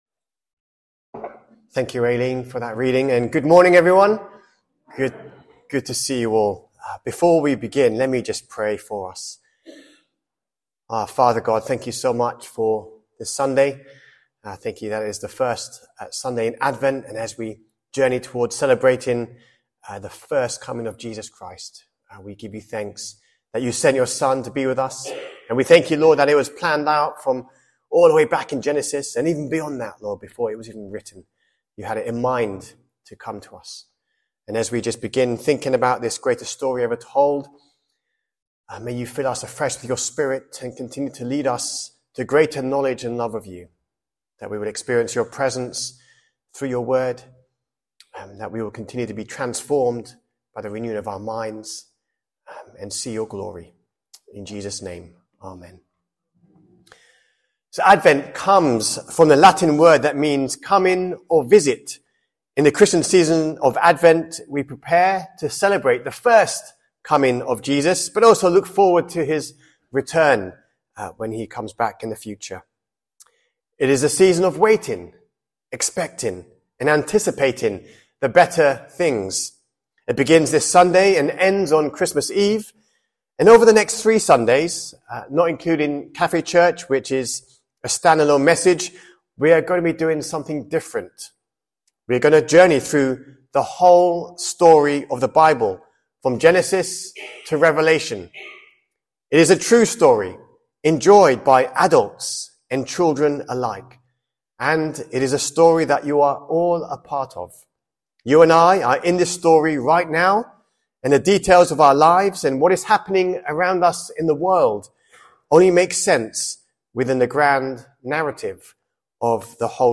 SERMON-1ST-DECEMBER.mp3